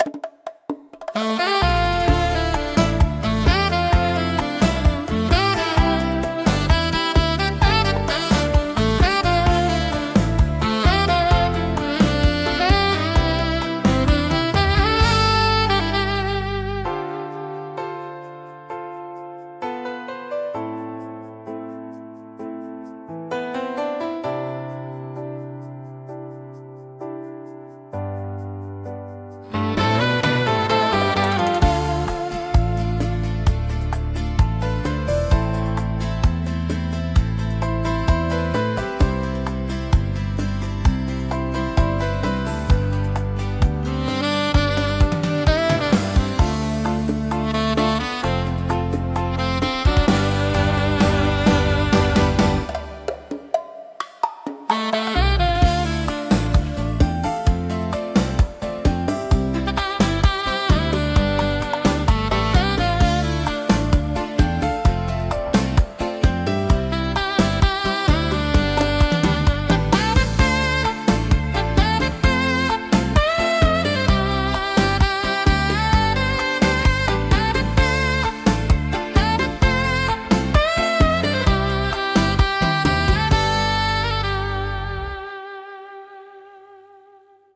música e arranjo: IA) instrumental 3